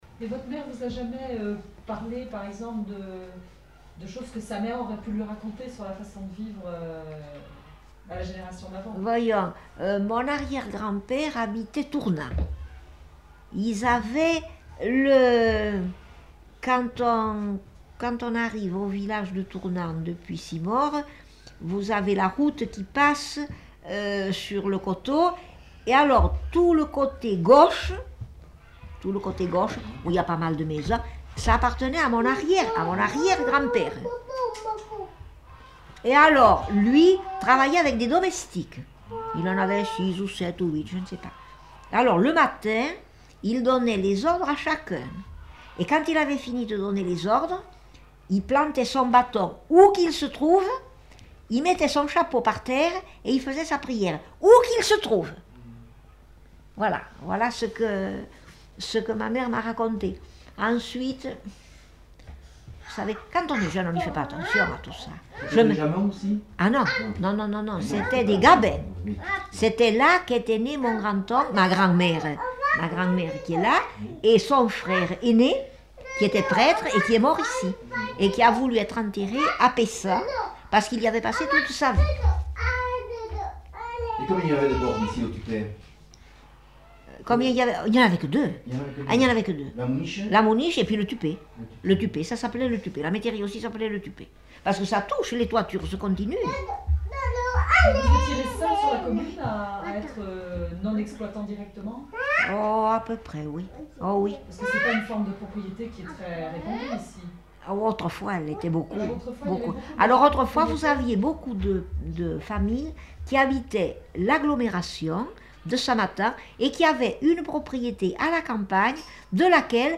Lieu : Noilhan
Genre : témoignage thématique